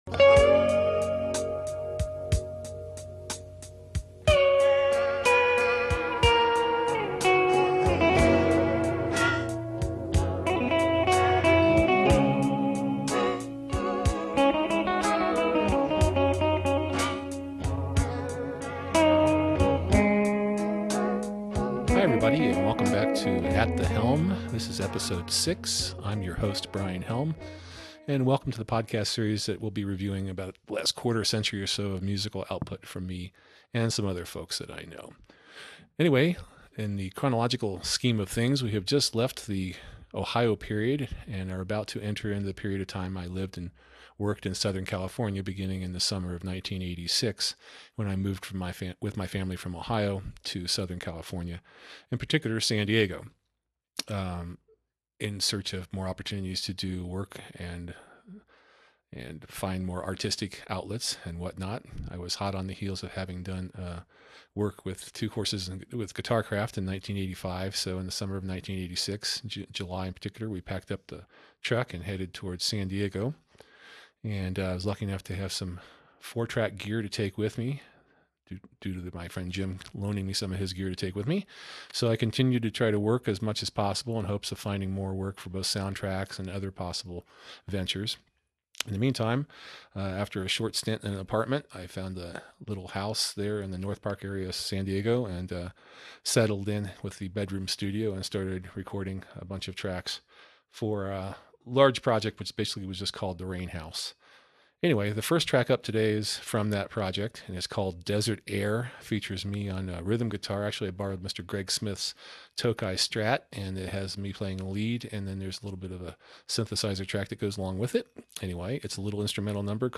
This show features solo material from late 1986 and early 1987 recorded in California,Â and includes the following tracks:
There’s a little bit of instrumental fun here for everyone, please do give it a listen and IÂ think you’ll enjoy it when you do.